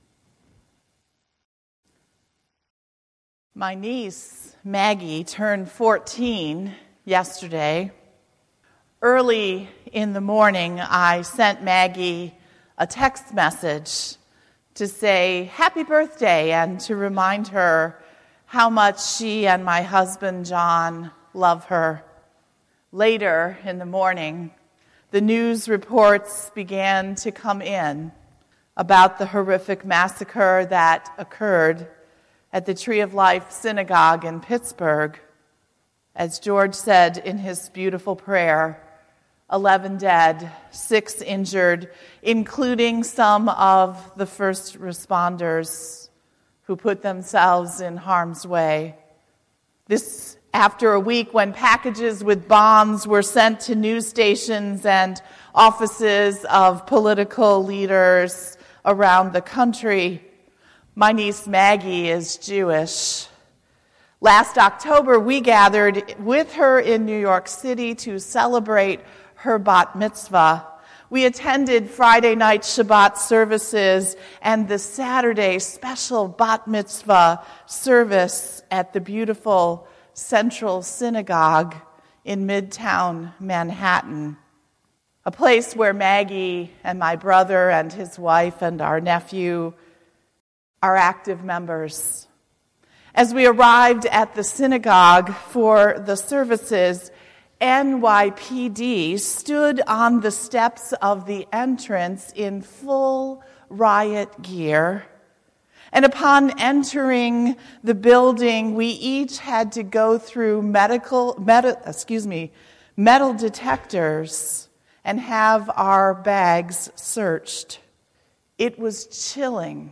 Oct2818-Sermon.mp3